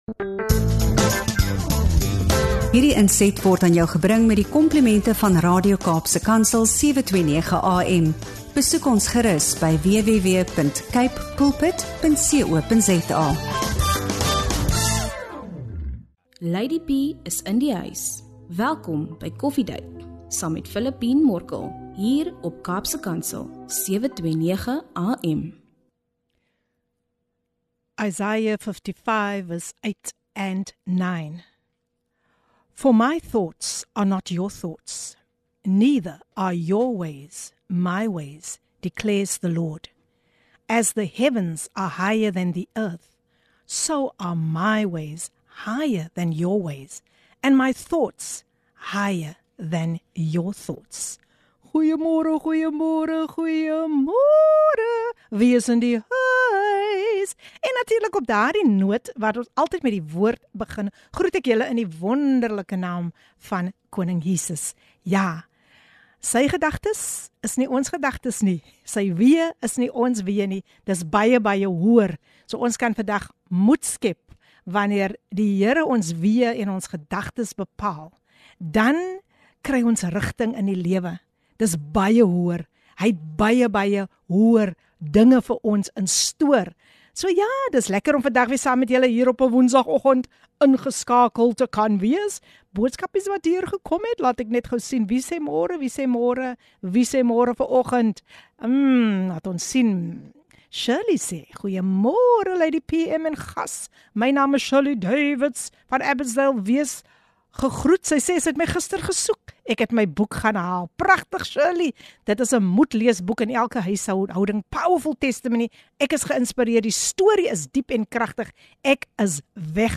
Luister in vir 'n pragtige gesprek oor geloof, gesin, en die krag van die keuse van God se pad.